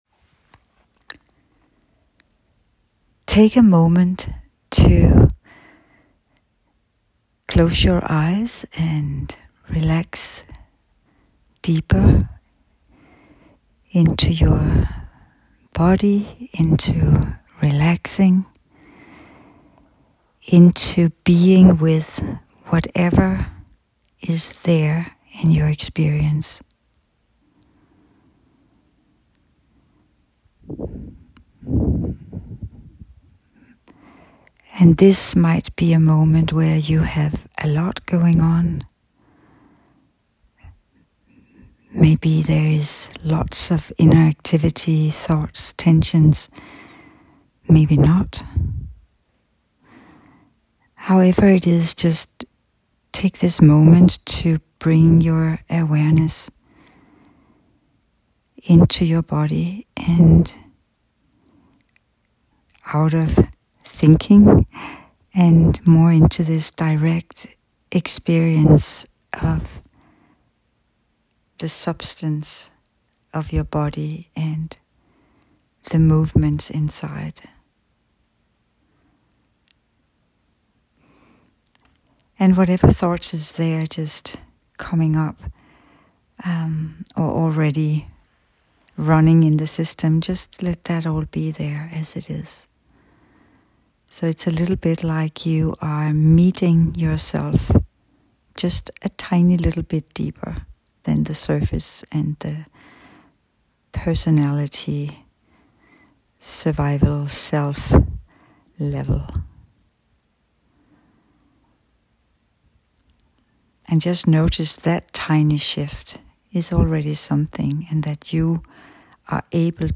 A free guided meditation inviting you to expand beyond the more dense experience of your self-body into finer levels of awareness, shifting your inner orientation to a different energy body within.
This is an unprepared transmission, spoken to you as we are together.